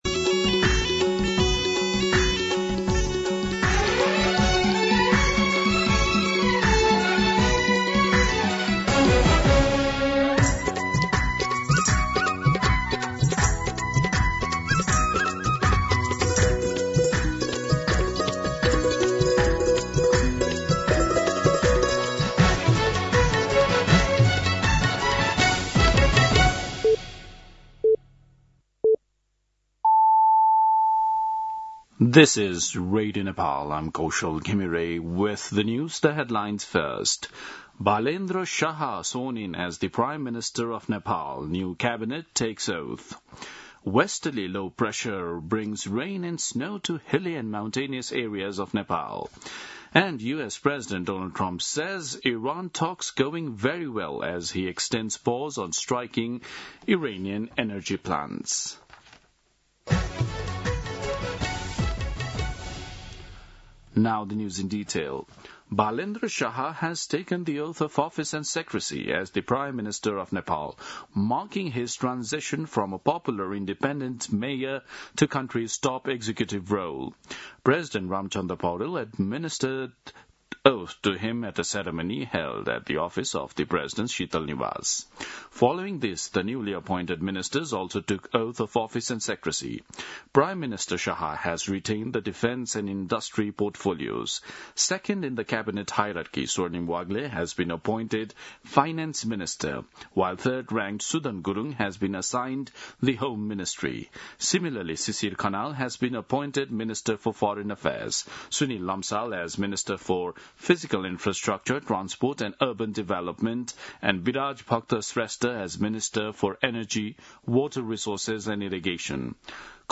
दिउँसो २ बजेको अङ्ग्रेजी समाचार : १३ चैत , २०८२
2-pm-English-News-4.mp3